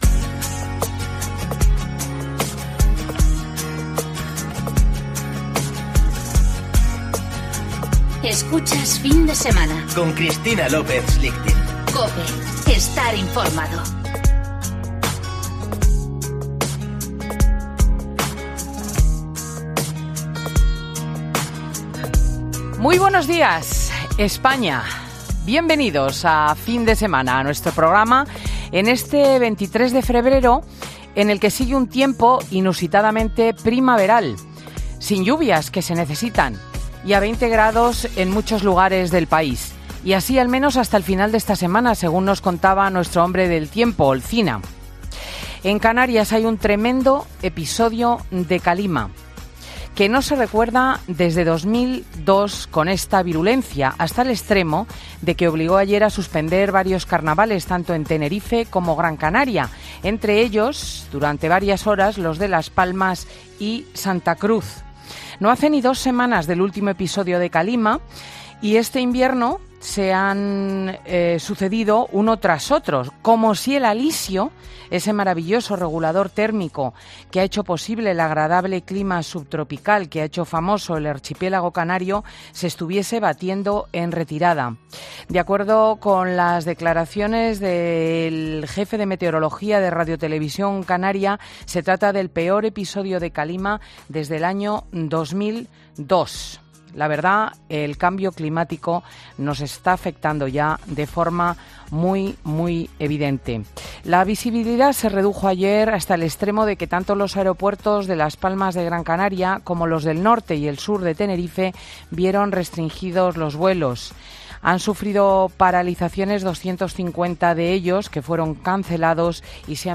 AUDIO: Ya puedes escuchar el monólogo de Cristina López Schlichting en 'Fin de Semana'